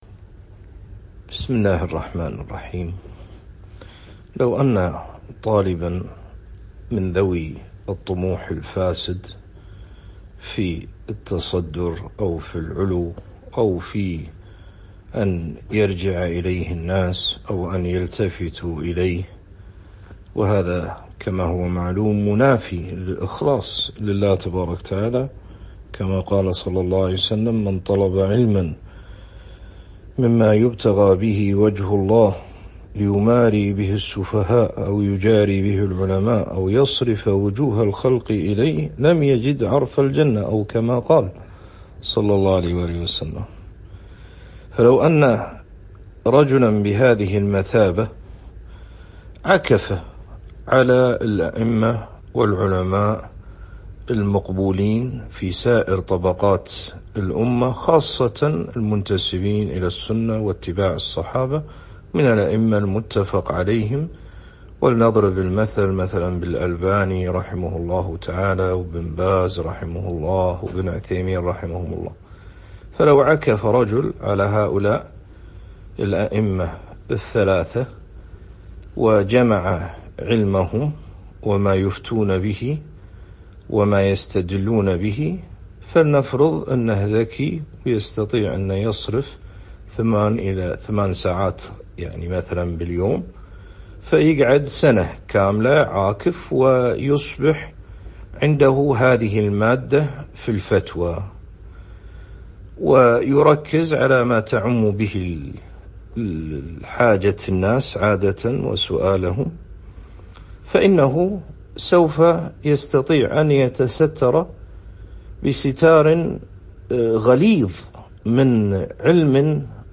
Format: MP3 Mono 22kHz 32Kbps (ABR)